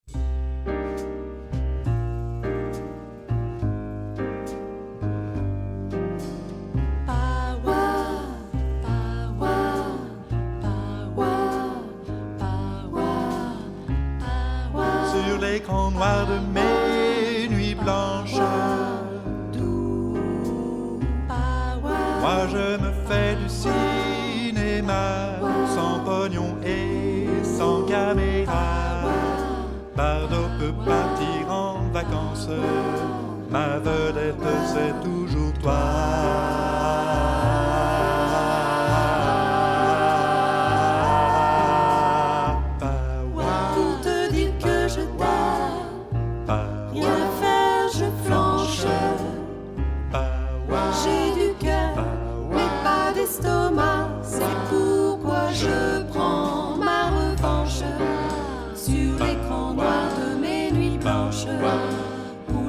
15 tracks arranged for mixed choir and jazz trio